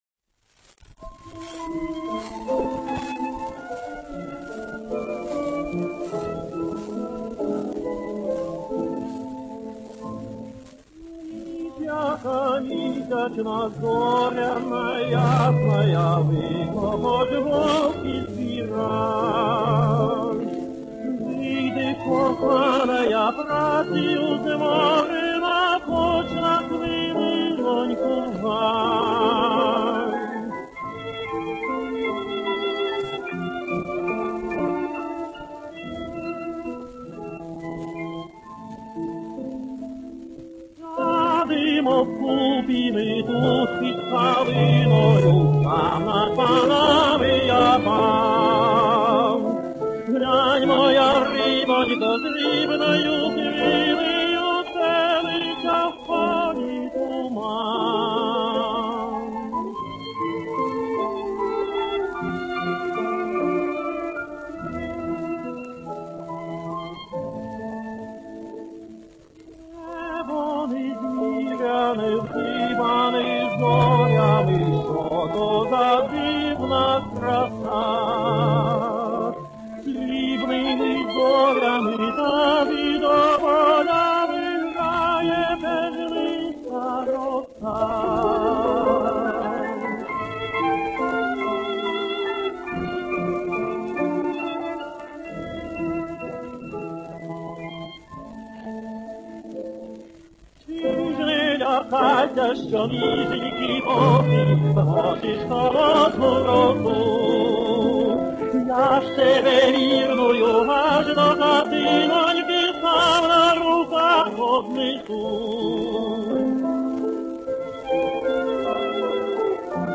фортепиано
скрипка
виолончель
Украинский романс «Hiч яка мiсячна